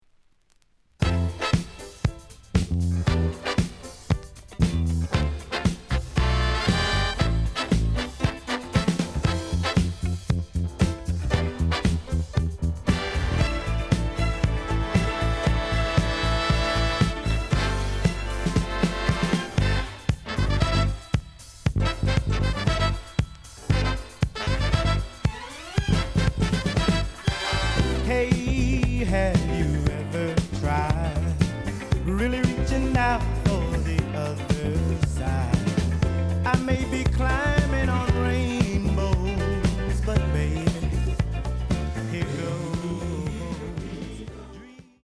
The audio sample is recorded from the actual item.
●Format: 7 inch
●Genre: Disco
Looks good, but slight noise on beginning of B side.)